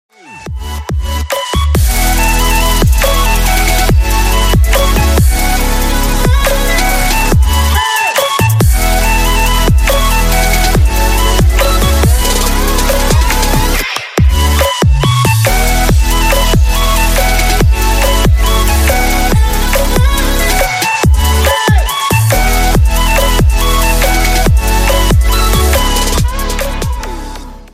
Громкие Рингтоны С Басами » # Рингтоны Без Слов
Рингтоны Ремиксы » # Рингтоны Электроника